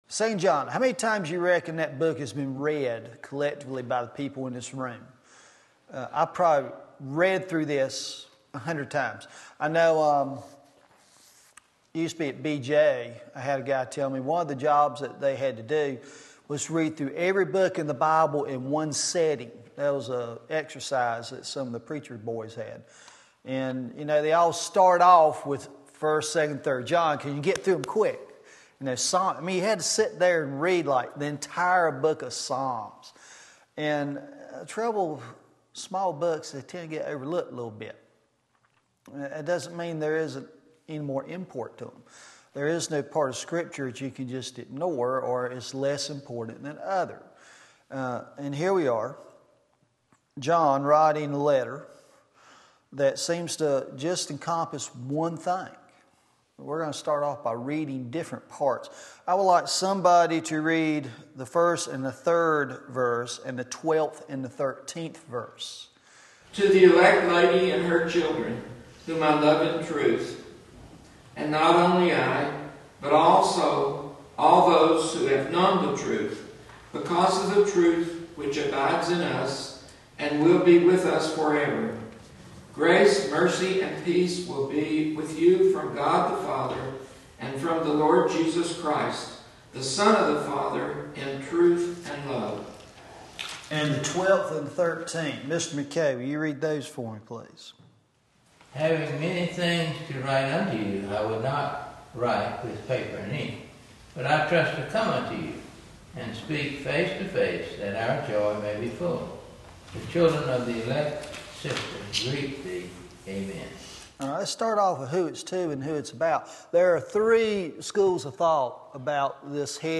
Sermon Link